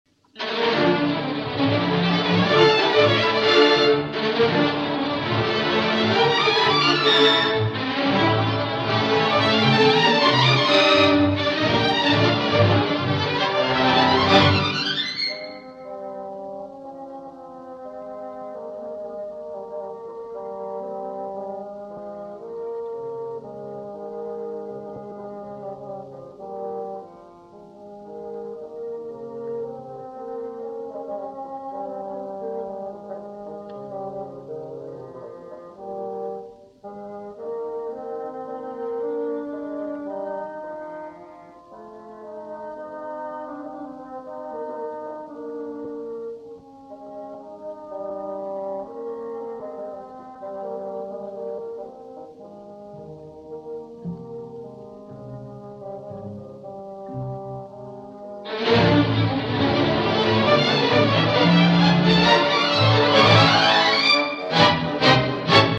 Классика
Симфоническая поэма для большого оркестра